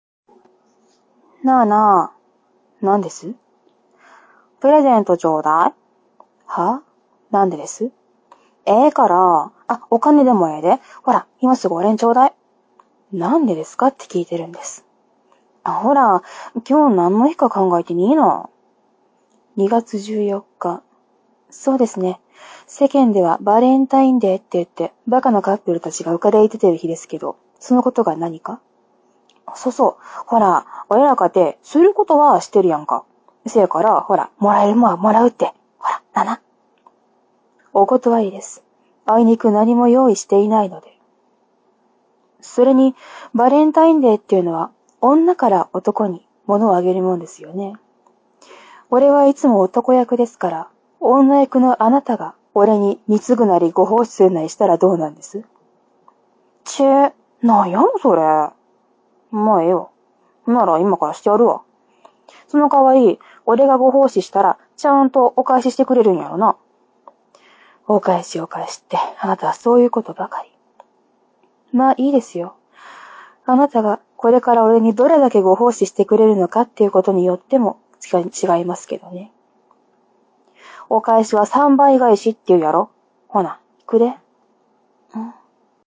その名の通り、ボイスデータですｖｖひとり劇場です。